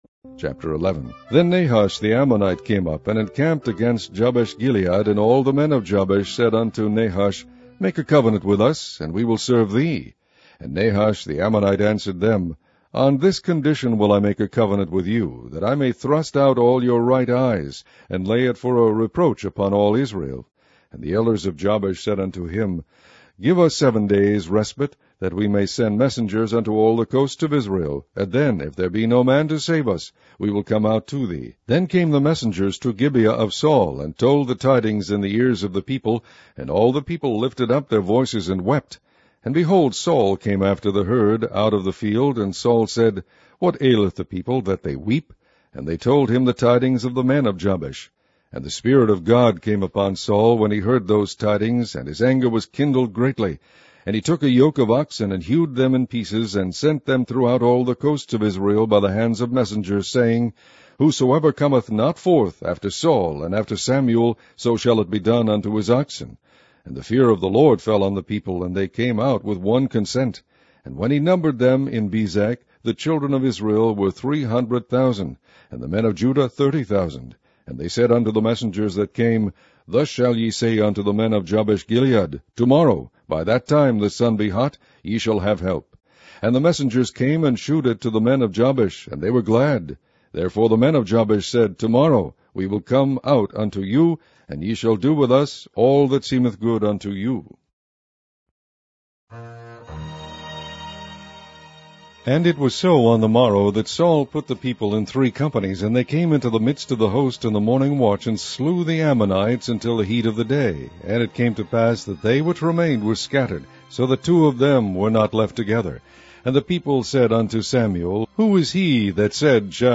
Online Audio Bible - King James Version - 1st Samuel